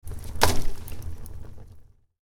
Fireplace Log Adding To Glowing Embers Sound Effect
Starting a fire in the fireplace, adding a large log to the glowing embers.
Fireplace-log-adding-to-glowing-embers-sound-effect.mp3